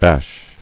[bash]